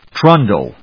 /trˈʌndl(米国英語)/